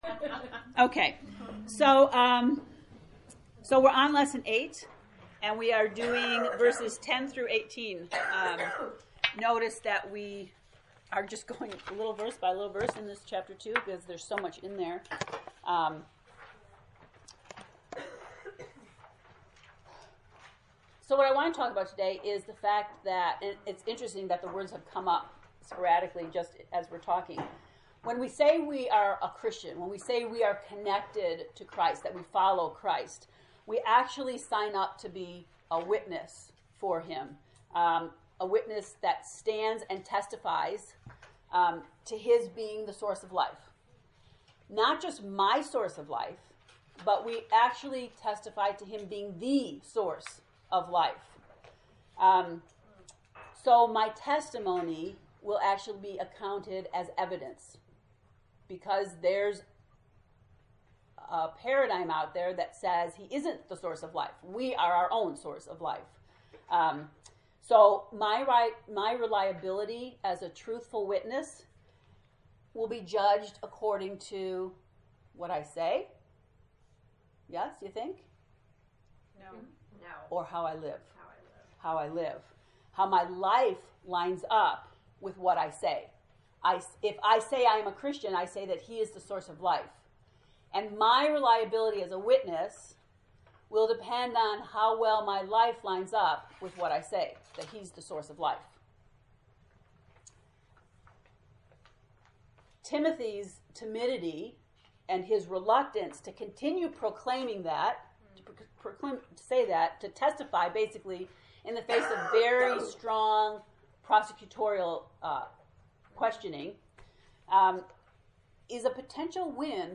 To listen to the lecture from lesson 8, “Kindling Truth”, click below: